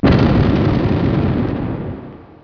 Totally Free War Sound Effects MP3 Downloads
Mortarcannon.mp3